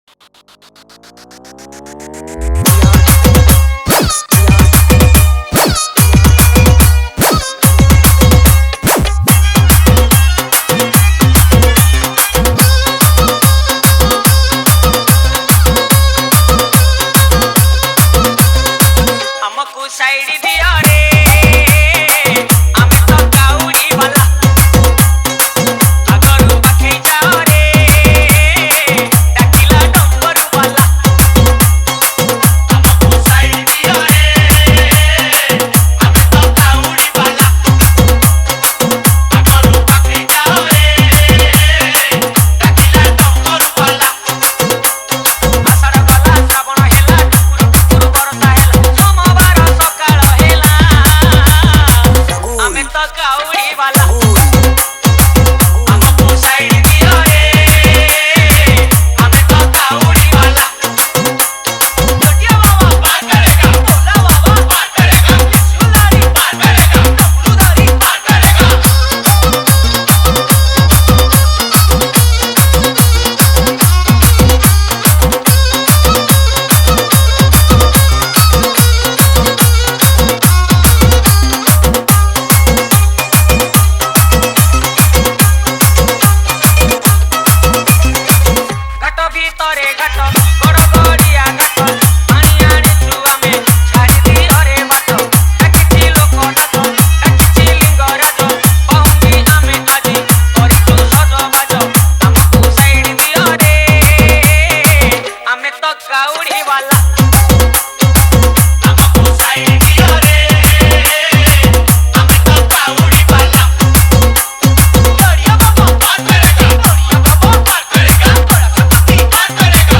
Category:  Odia Bhajan Dj 2022